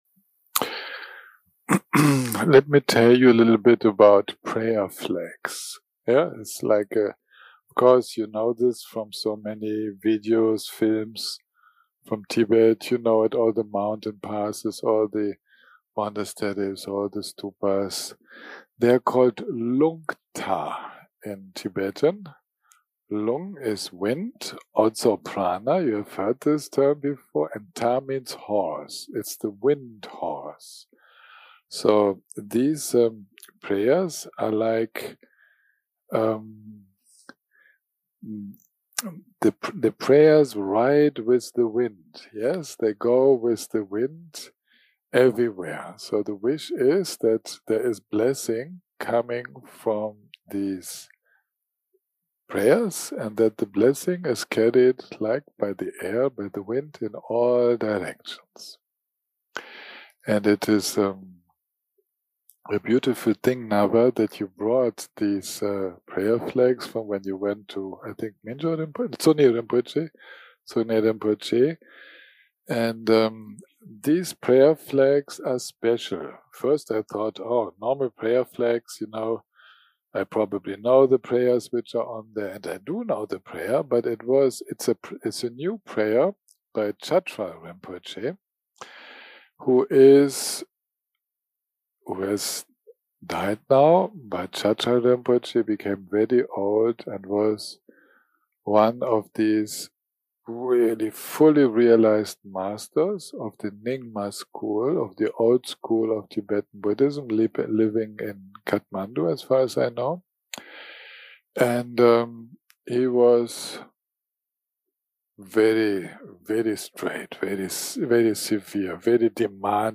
day 8 - recording 28 - Afternoon - Talk + Meditation - Prayer Flags
day 8 - recording 28 - Afternoon - Talk + Meditation - Prayer Flags 0:00 0:00 סוג ההקלטה: Dharma type: Dharma Talks שפת ההקלטה: Dharma talk language: English